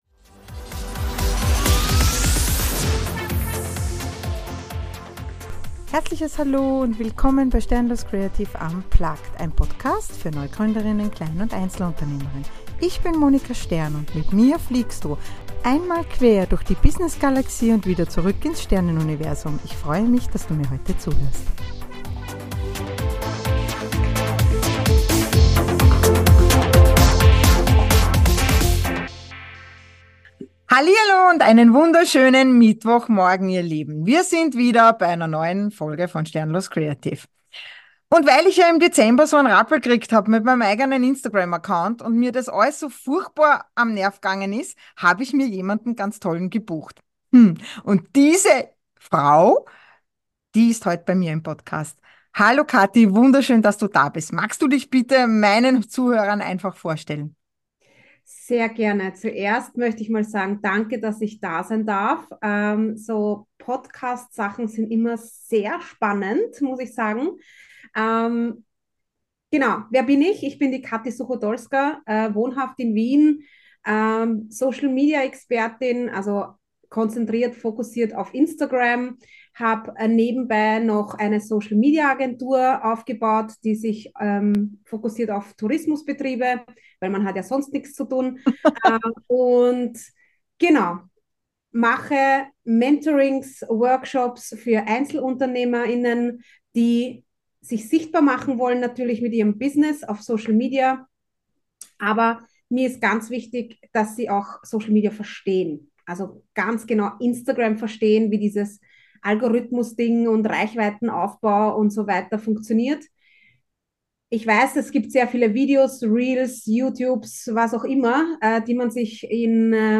INTERVIEW Wie muss ich mich zeigen auf Instagram. Wie bekomme ich neue Follower oder besser neue Kunden*innen.